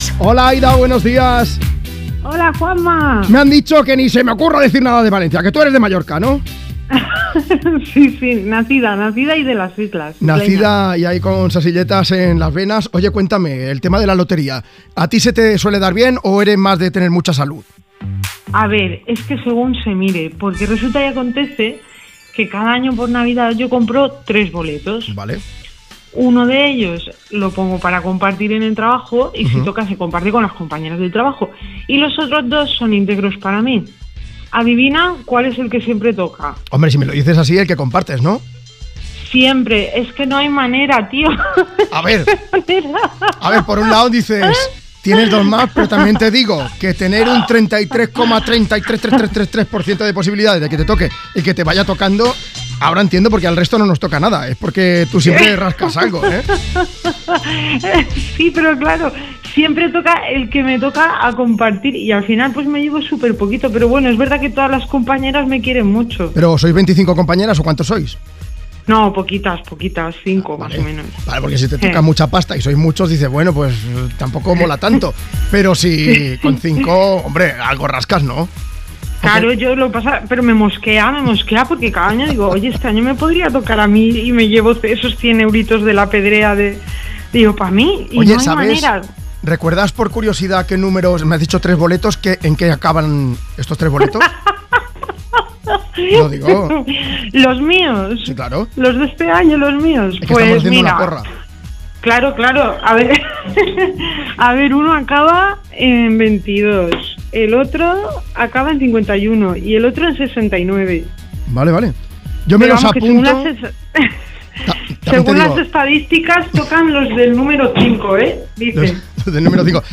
una oyente de Me Pones de Mallorca